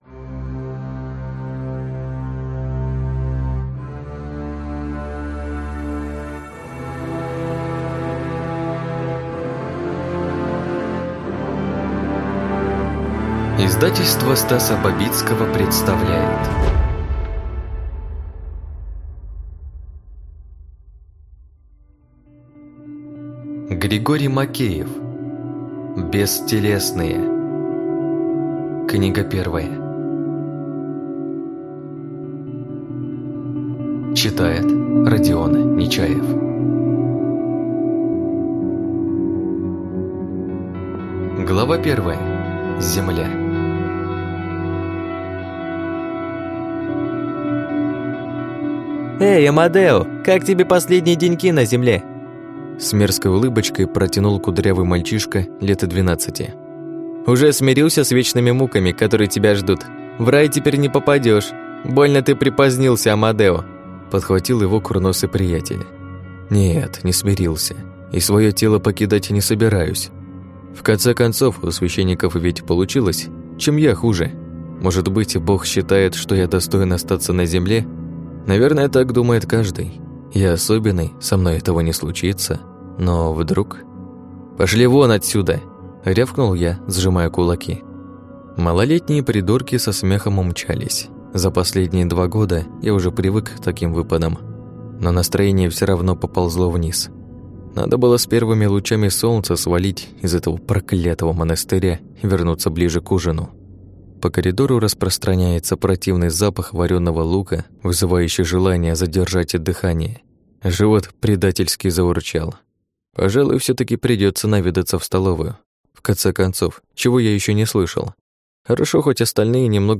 Аудиокнига Бестелесные. Книга 1 | Библиотека аудиокниг